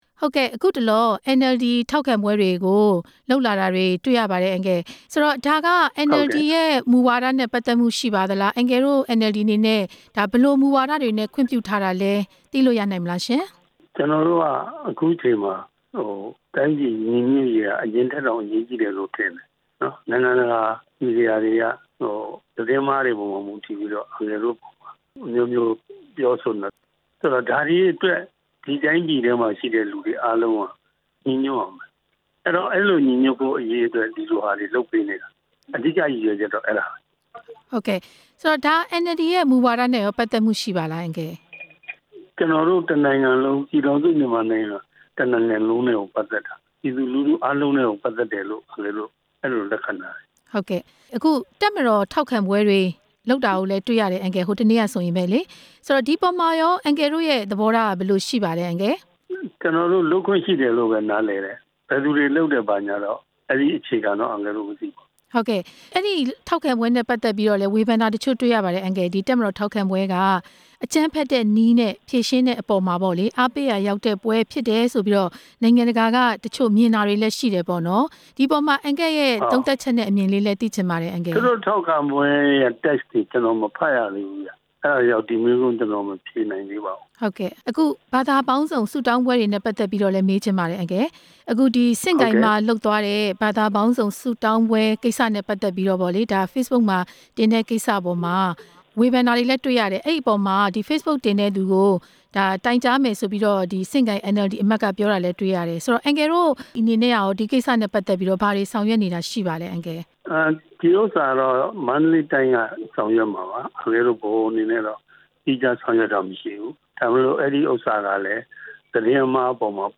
ထောက်ခံပွဲတွေ ဘာကြောင့် လုပ်နေရသလဲ၊ NLD ပါတီနဲ့ မေးမြန်းချက်